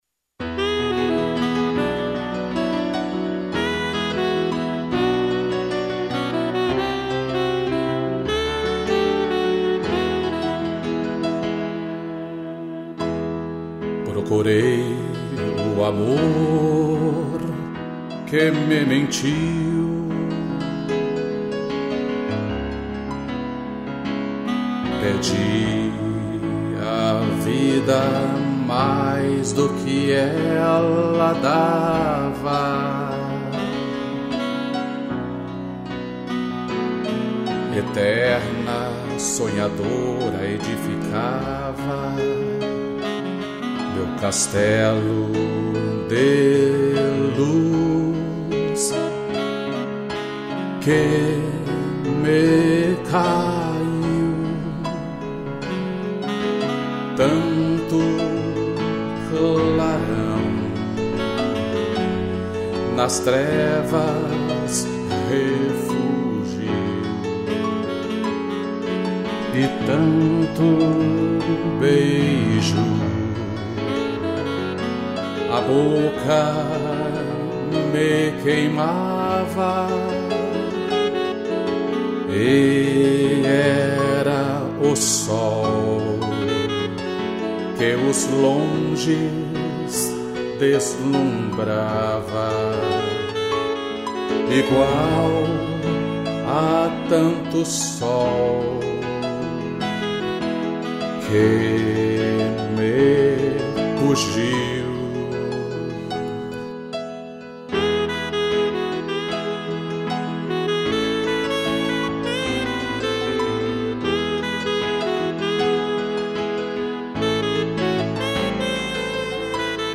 2 pianos e sax